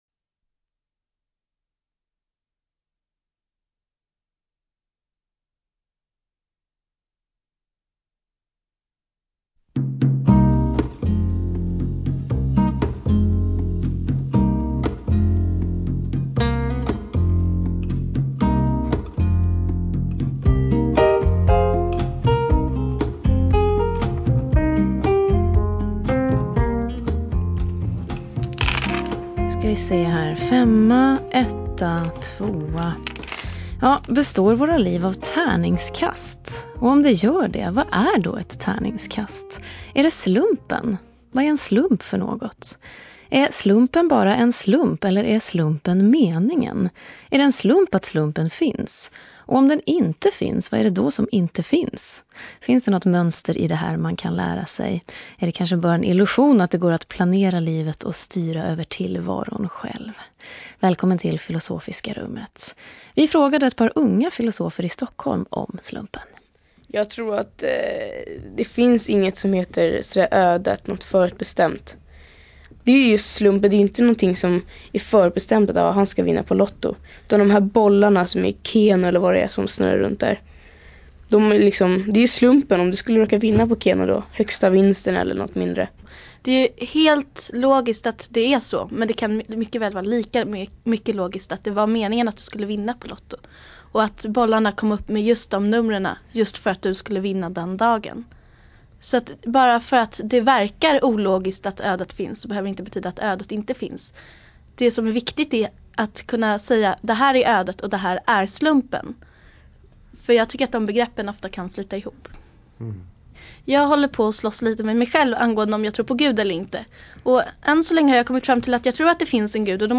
Samtal om slump och mening